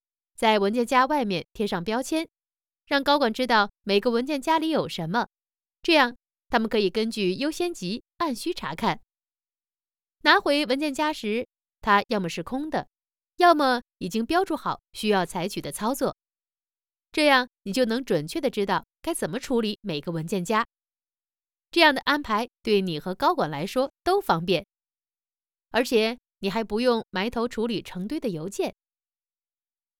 Chinese_Female_044VoiceArtist_2Hours_High_Quality_Voice_Dataset
Text-to-Speech